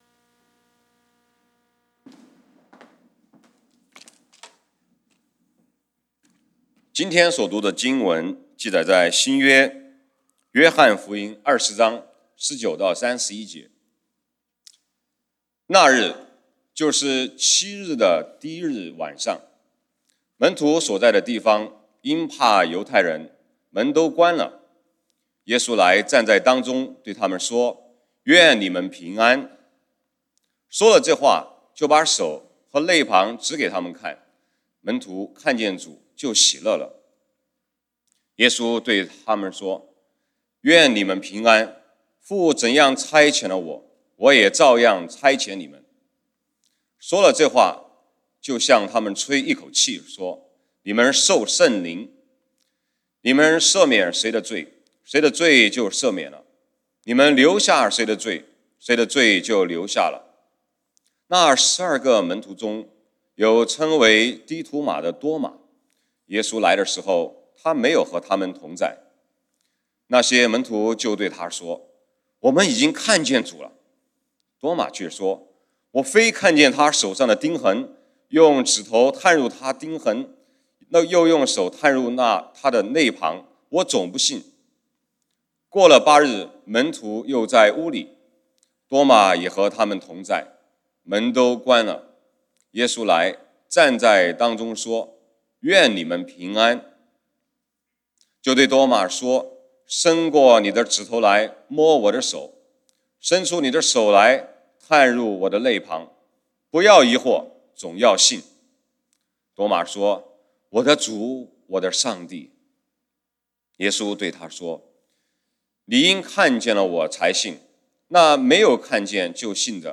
講道經文：《約翰福音》John 20:19-31 本週箴言：《約翰福音》John 14:27 「耶穌說：『我留下平安給你們，我將我的平安賜給你們。